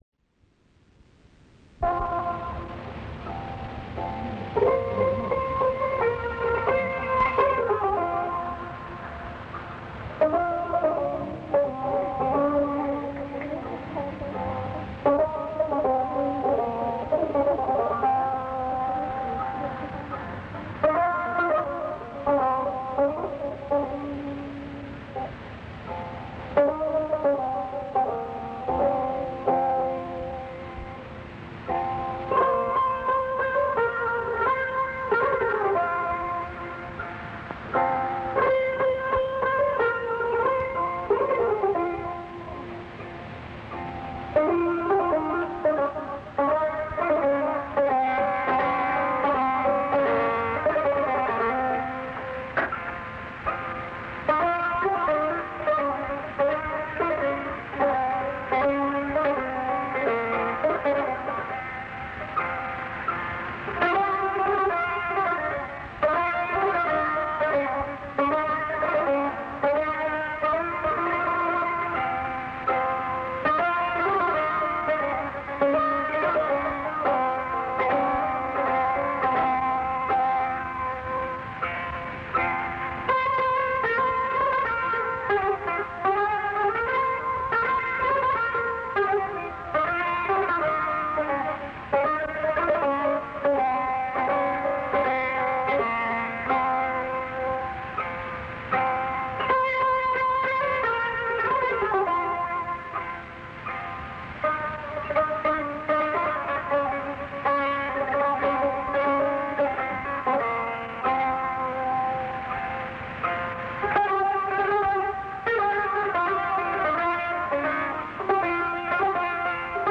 (بشنوید) اجرای خصوصی لطفی و شجریان
فرارو- محمد رضا لطفی نوازنده چیره دست تار و سه تار در طول دوران برپار زندگی هنری خود با خوانندگان بسیاری همکاری داشت و آثار ماندگاری به تاریخ موسیقی کشورمان افزود.